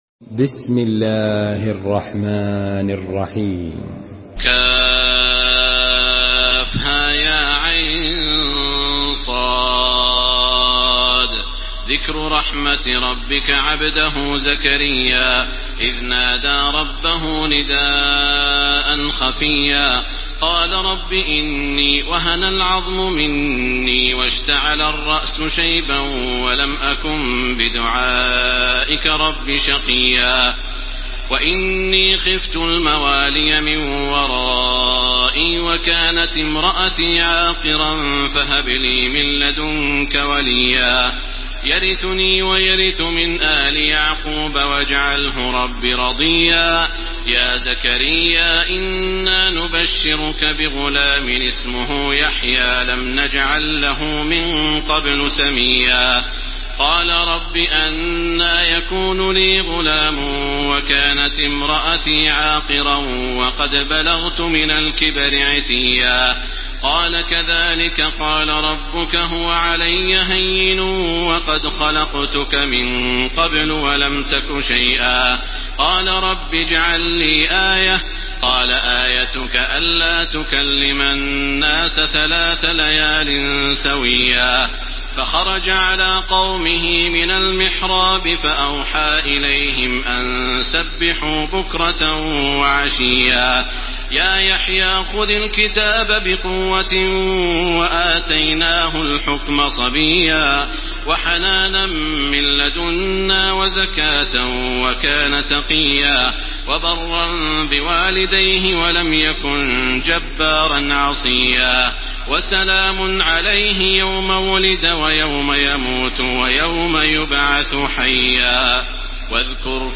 تحميل سورة مريم بصوت تراويح الحرم المكي 1431
مرتل